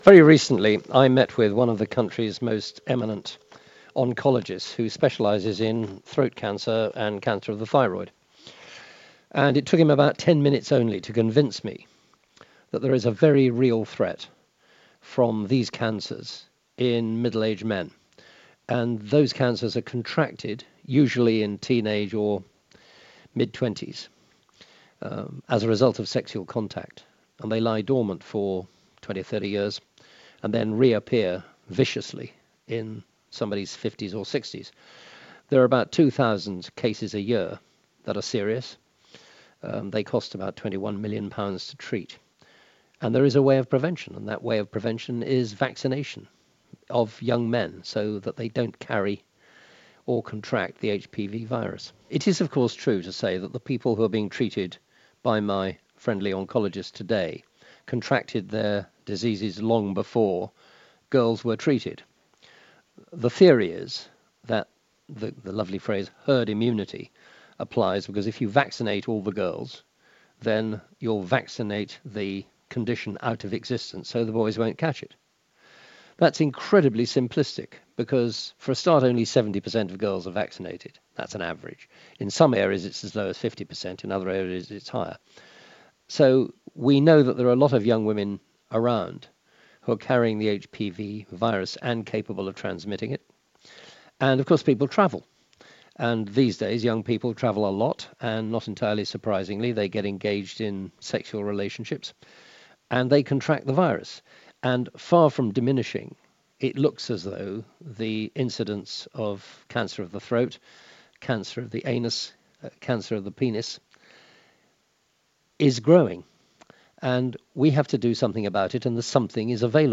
Listen: North Thanet MP Sir Roger Gale speaks ahead of the debate to make the HPV vaccine available to teenage boys